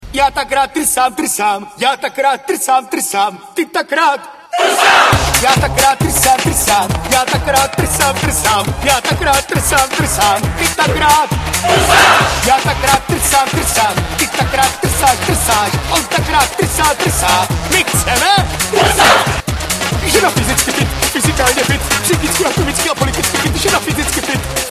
spev zvierat 0:24